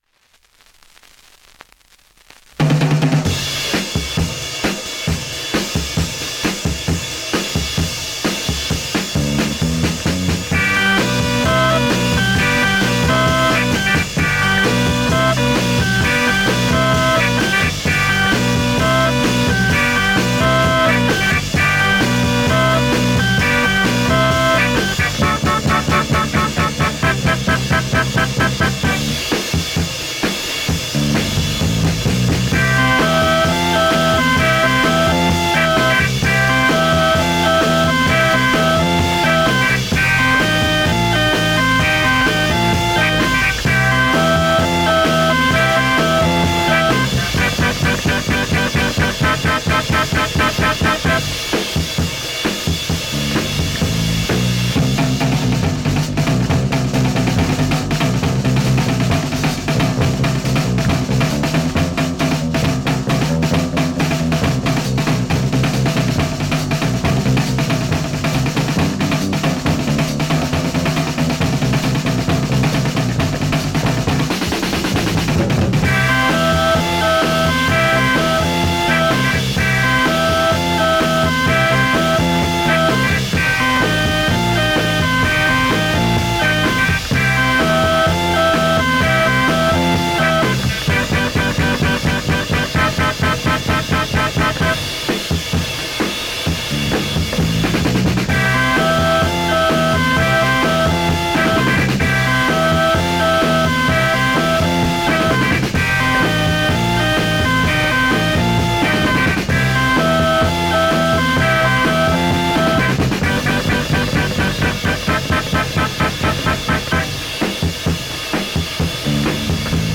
◆盤質両面/VG薄いスレ多め◆普通に聴けます◆